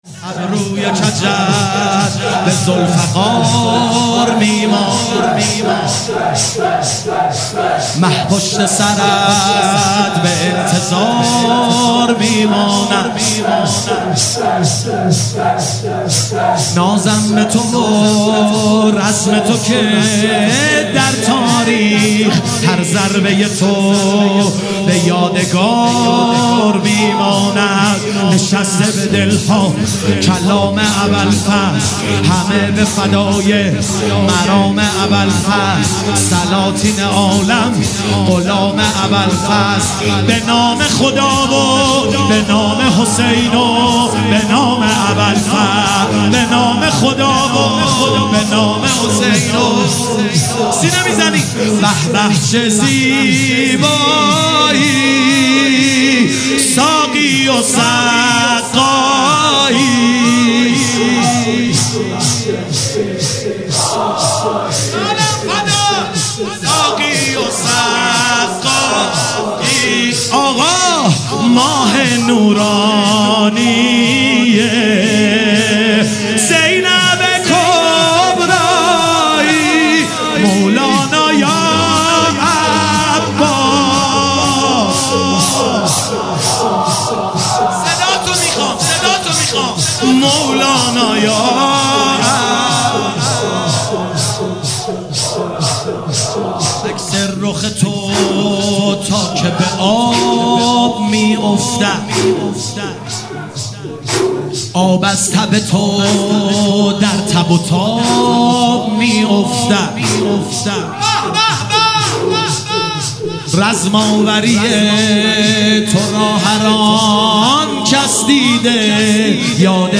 چاوش محرم 1399 | هیئت بیت الرقیه (س) تهران